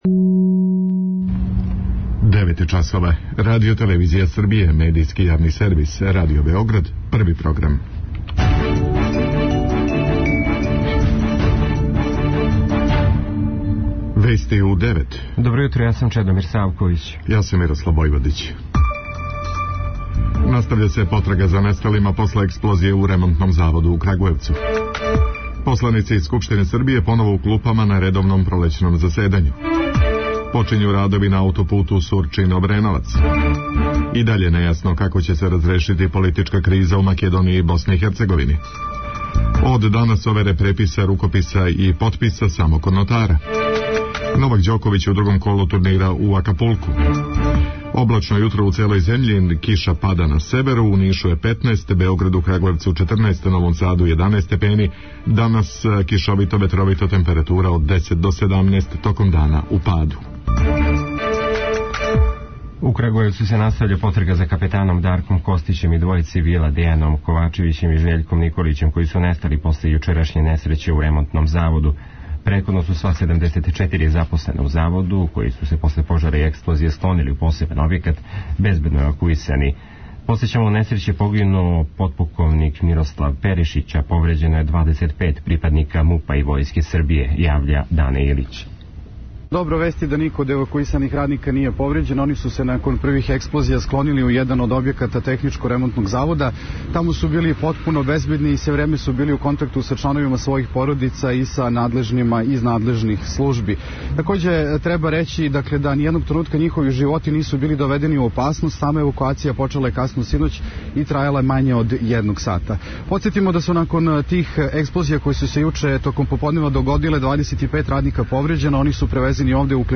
преузми : 3.66 MB Вести у 9 Autor: разни аутори Преглед најважнијиx информација из земље из света.